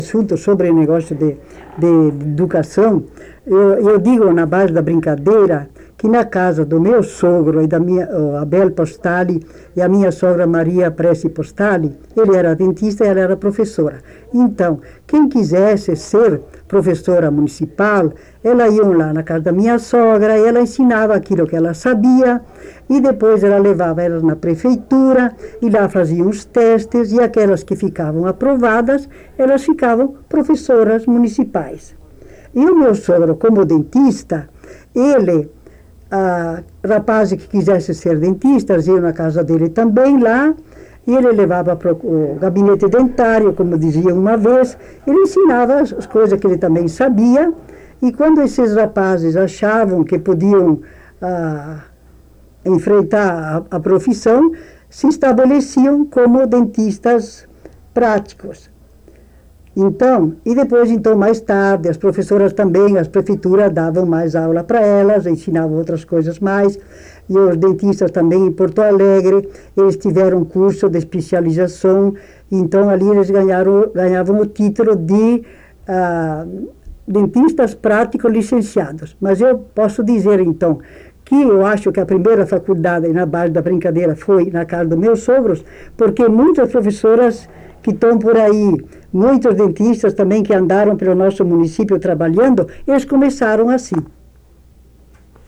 Unidade Banco de Memória Oral